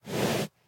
Minecraft Version Minecraft Version 25w18a Latest Release | Latest Snapshot 25w18a / assets / minecraft / sounds / mob / horse / breathe1.ogg Compare With Compare With Latest Release | Latest Snapshot
breathe1.ogg